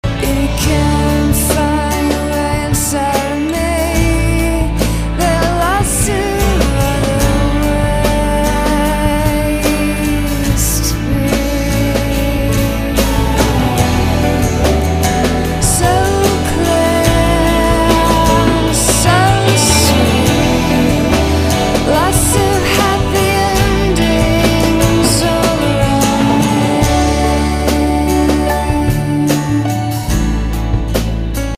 groovy tunes